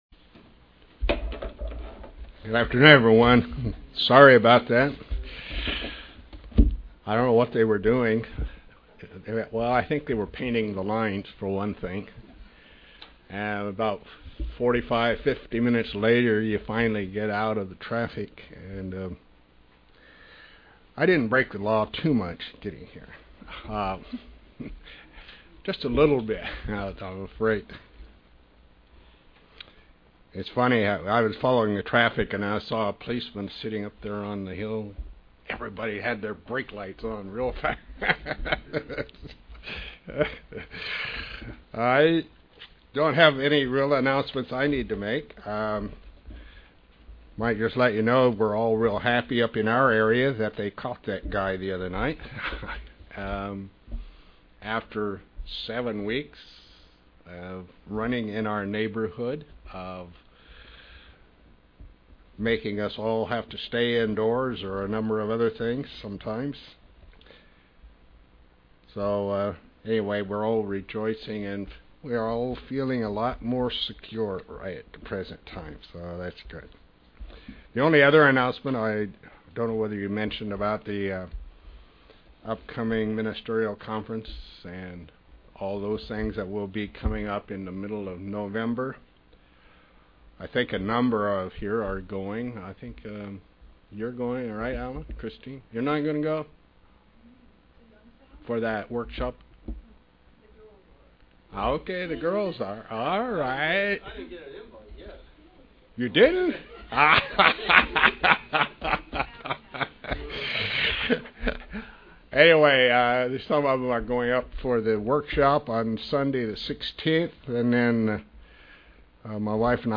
Given in York, PA
UCG Sermon Studying the bible?